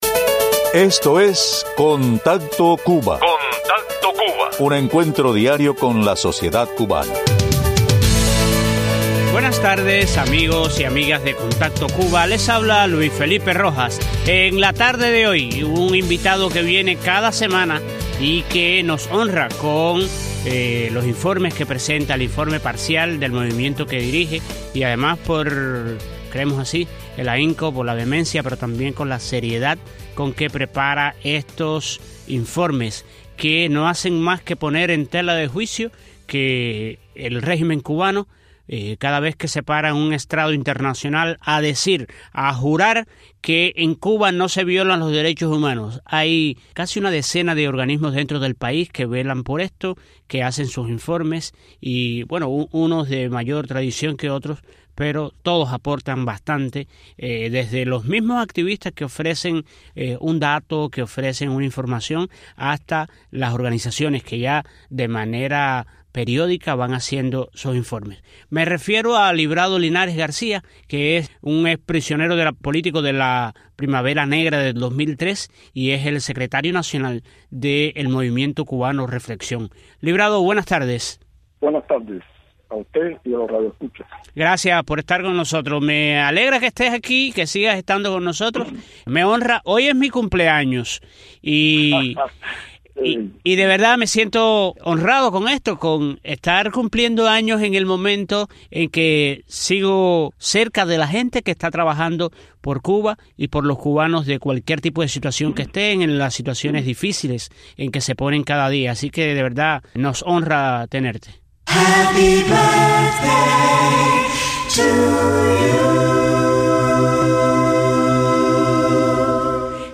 Informe semanal del Movimiento Cubano Reflexión